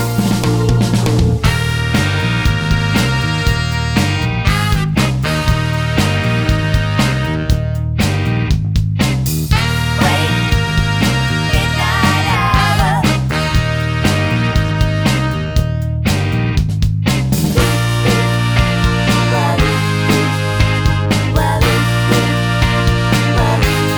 One Semitone Down Soundtracks 2:22 Buy £1.50